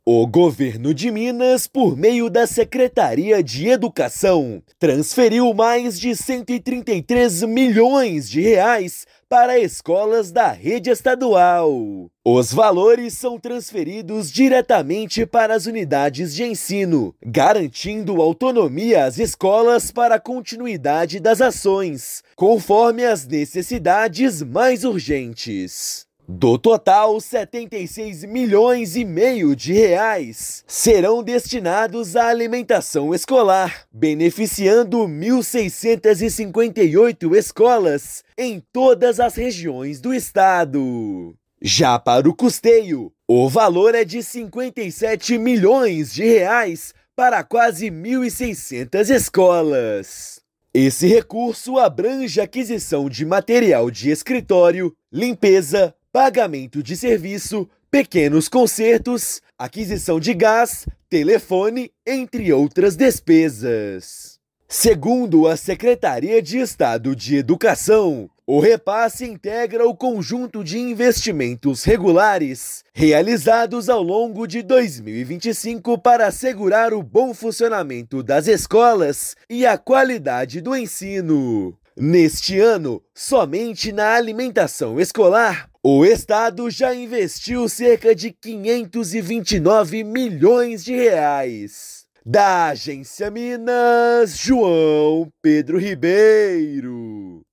Recursos chegaram às contas das escolas na sexta-feira (17/10) e reforçam ações de manutenção e alimentação escolar. Ouça matéria de rádio.